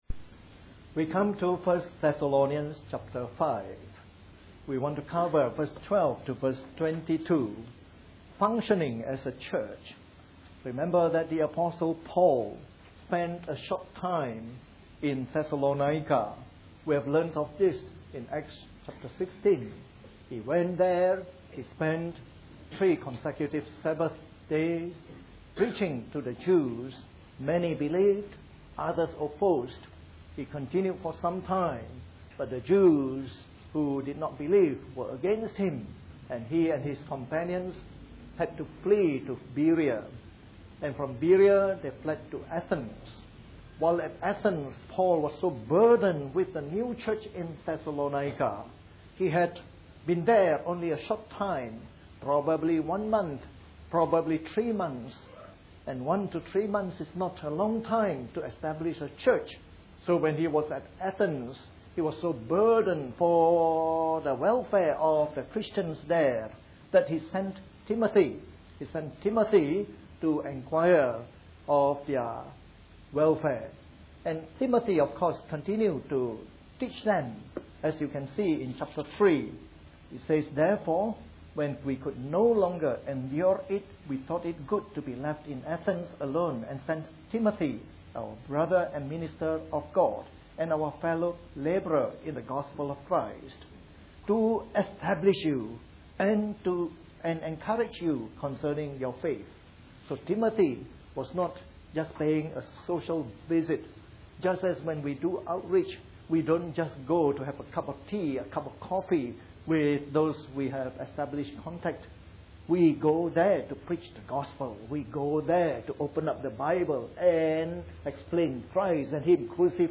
A sermon in the morning service from our series on 1 Thessalonians.